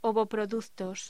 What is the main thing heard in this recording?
Locución: Ovoproductos voz